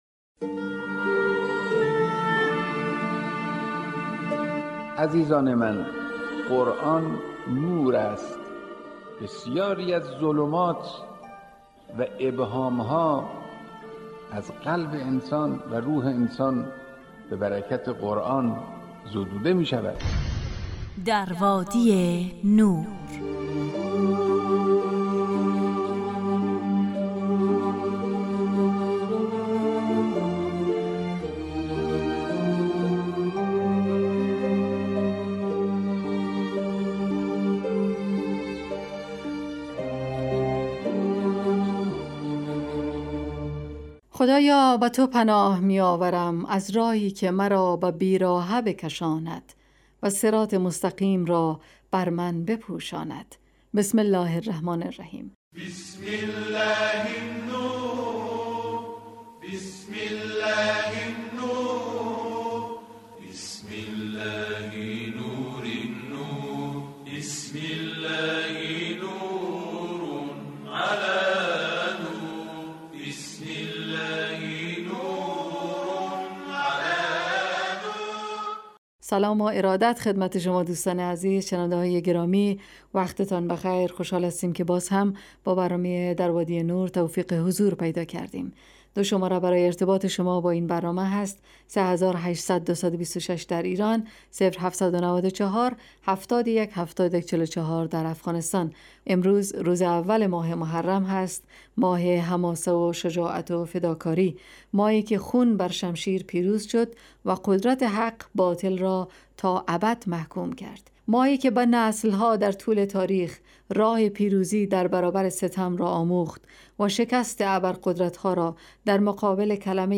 در وادی نور برنامه ای 45 دقیقه ای با موضوعات قرآنی روزهای فرد: ( قرآن و عترت،طلایه داران تلاوت ، دانستنیهای قرآنی، ایستگاه تلاوت، تفسیر روان و آموزه ها...